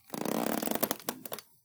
Crossbow_StringPull 02.wav